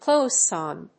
アクセントclóse on…